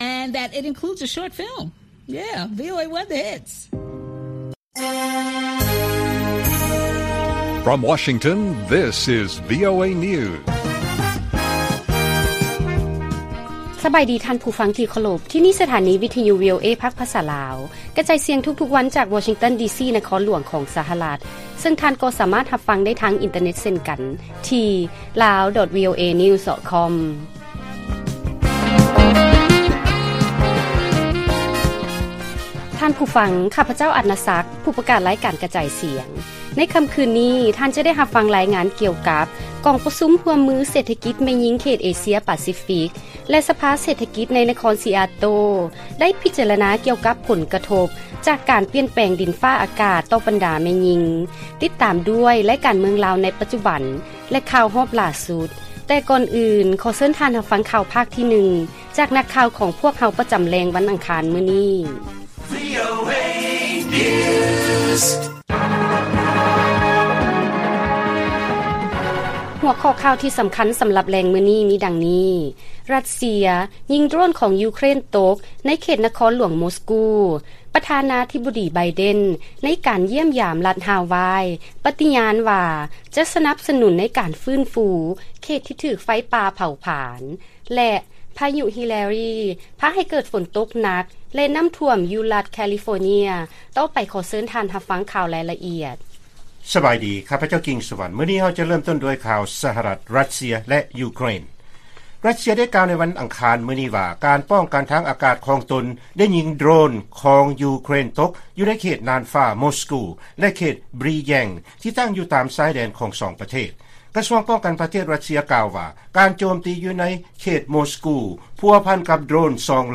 ລາຍການກະຈາຍສຽງຂອງວີໂອເອ ລາວ: ຣັດເຊຍ ຍິງໂດຣນຂອງຢູເຄຣນຕົກ ໃນເຂດນະຄອນຫຼວງມົສກູ